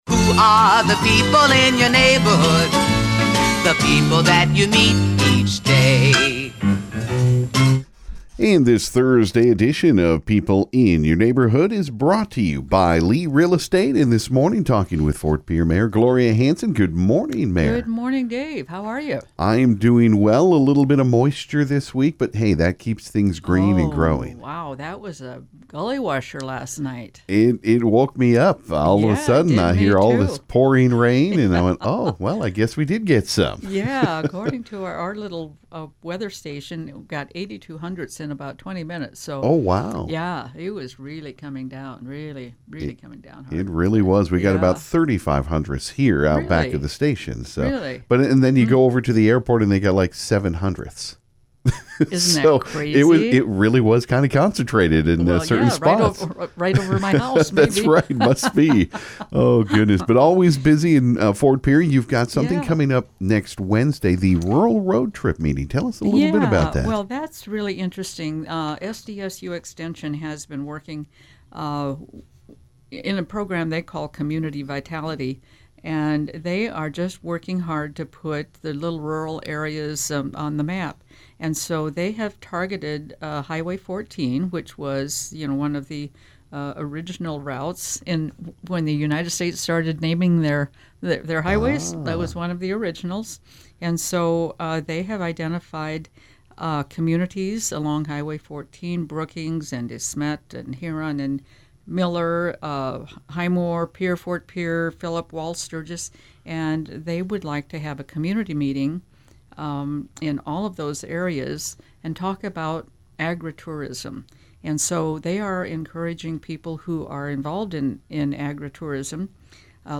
She also touched on the Indian Relays on the 20th & 21st, some summer and full-time positions the city would like to fill and a few more things. Listen to the interview.